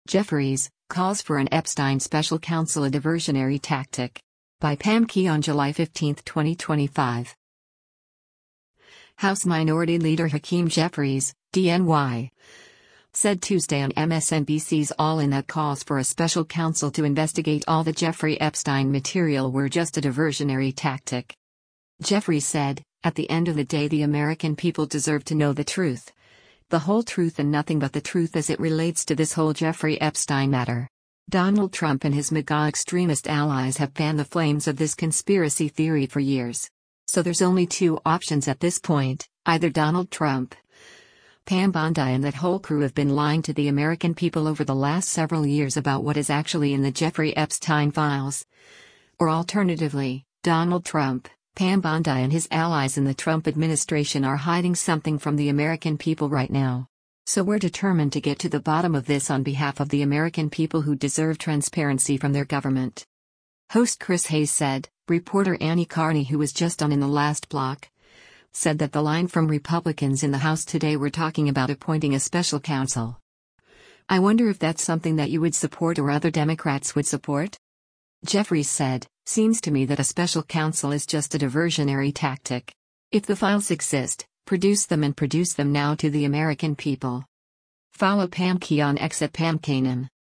House Minority Leader Hakeem Jeffries (D-NY) said Tuesday on MSNBC’s “All In” that calls for a special counsel to investigate all the Jeffrey Epstein material were “just a diversionary tactic.”